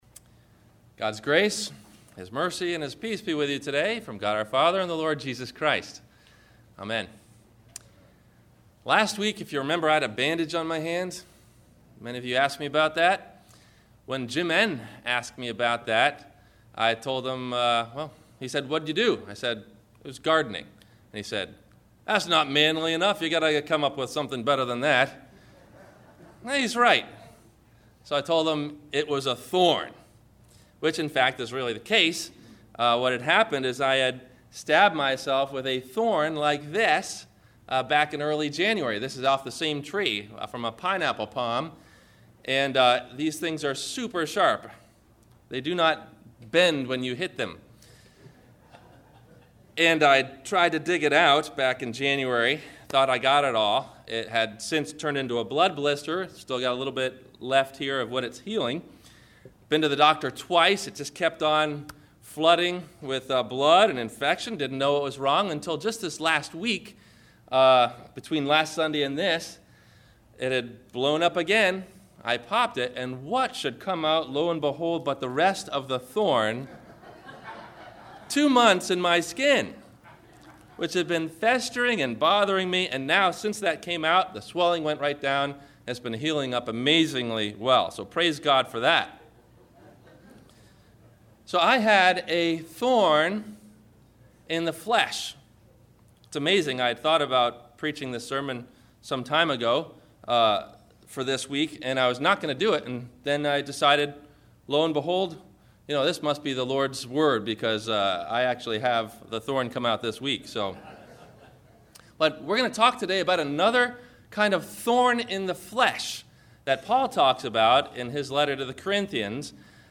The Breastplate of Righteousness – Sermon – September 28 2008